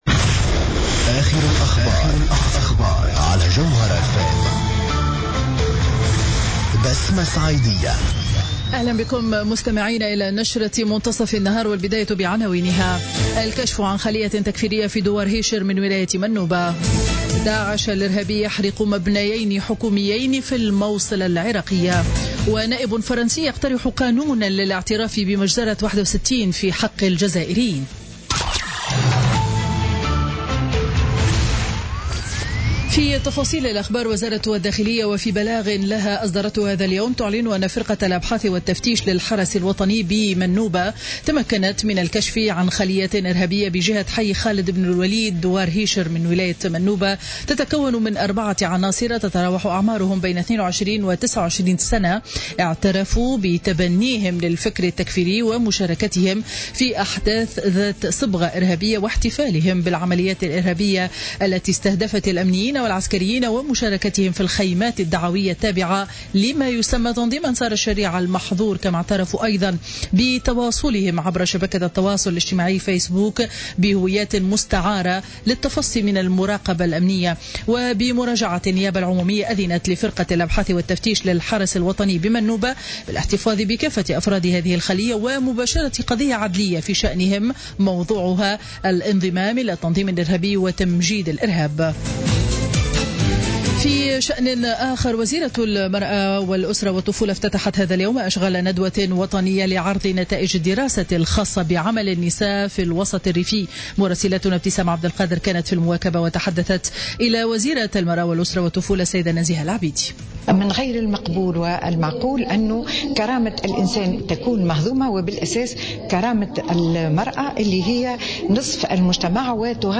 Journal Info 12h00 du mercredi 19 octobre 2016